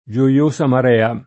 gioioso [JoL1So] agg.